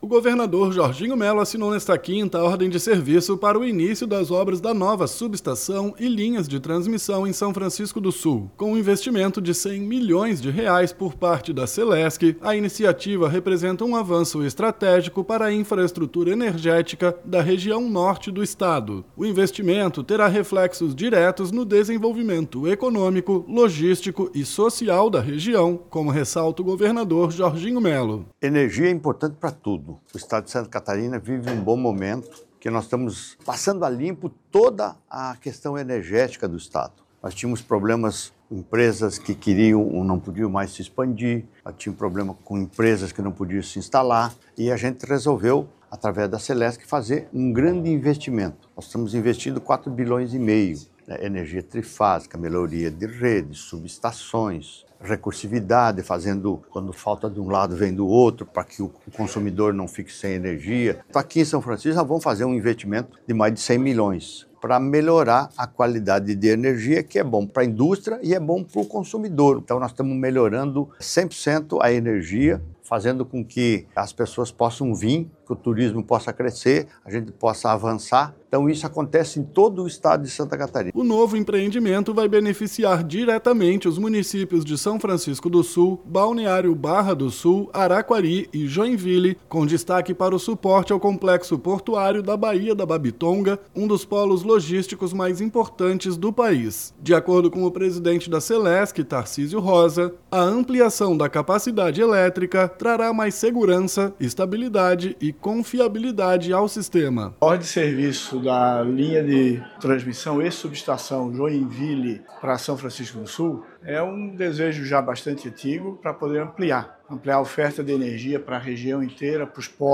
BOLETIM – Governador Jorginho Mello anuncia investimentos de R$ 100 milhões em nova subestação para região de São Francisco do Sul
O investimento terá reflexos diretos no desenvolvimento econômico, logístico e social da região, como ressalta o governador Jorginho Mello: